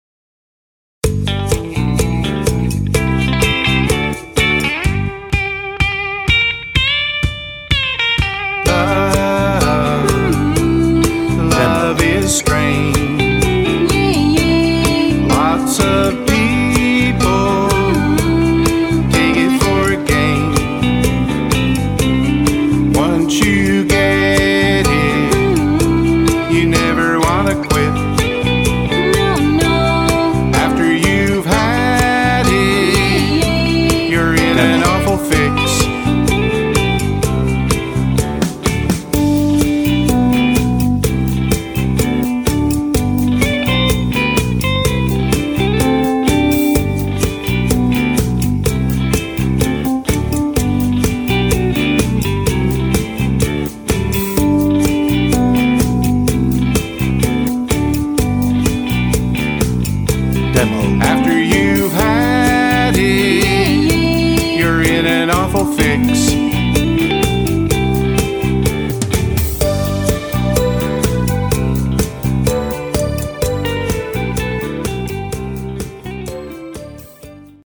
Instrumental (Female BGV) Instrumental (Male BGV)